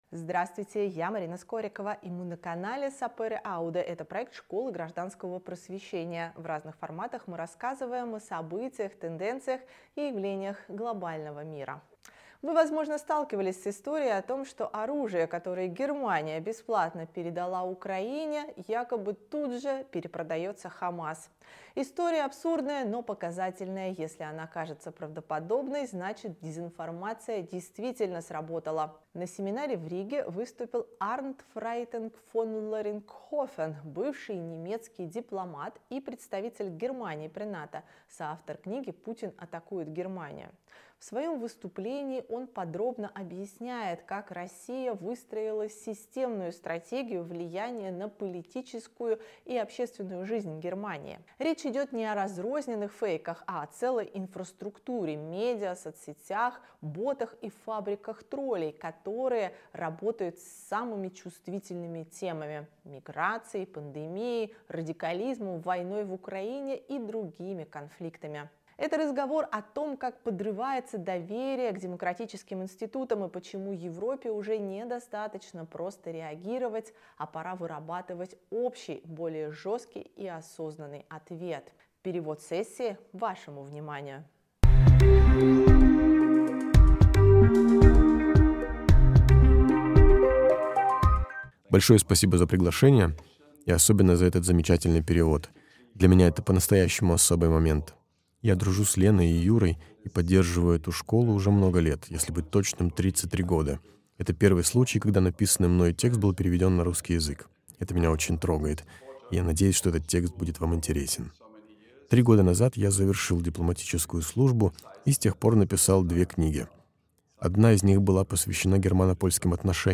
Выступление записано на семинаре Школы гражданского просвещения в декабре 2025.